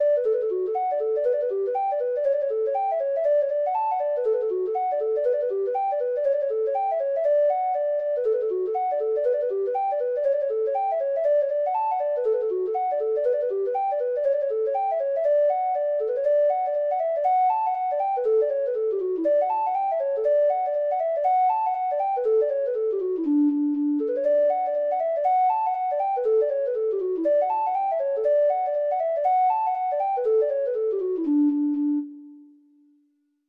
Traditional Trad. Real Thing, The (Irish Folk Song) (Ireland) Treble Clef Instrument version
Traditional Music of unknown author.
Irish